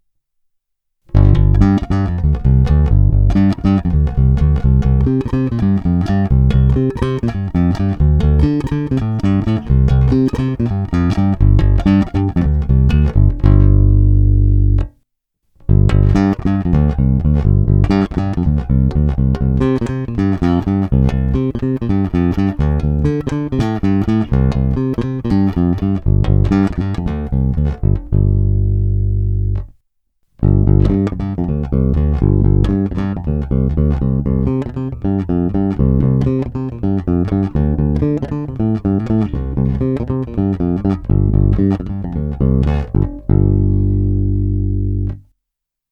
Masívní basy, vrčivé středy, kousavé výšky.
Není-li uvedeno jinak, následující nahrávky jsou nahrány rovnou do zvukovky a dále jen normalizovány.